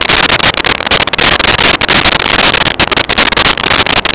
Sfx Amb City Aquil Loop
sfx_amb_city_aquil_loop.wav